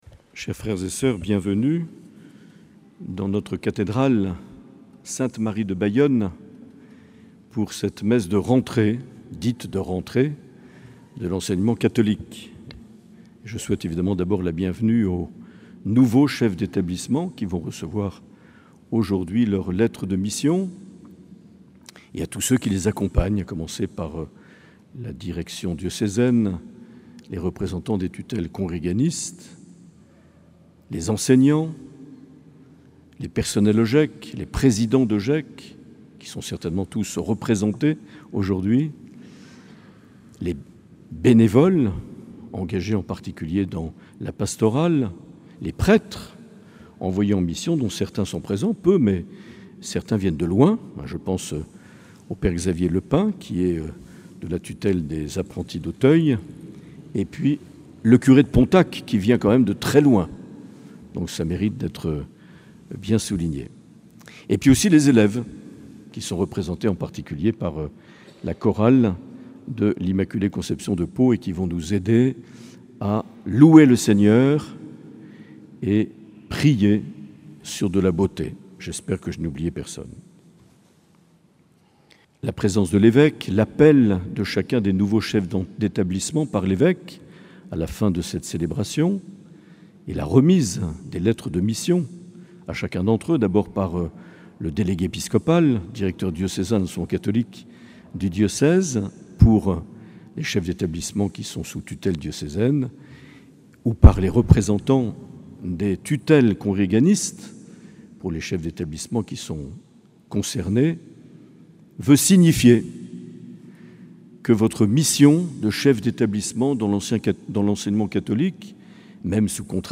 8 septembre 2021 - Cathédrale de Bayonne - Messe de rentrée de l'Enseignement Catholique
Accueil \ Emissions \ Vie de l’Eglise \ Evêque \ Les Homélies \ 8 septembre 2021 - Cathédrale de Bayonne - Messe de rentrée de l’Enseignement (...)
Une émission présentée par Monseigneur Marc Aillet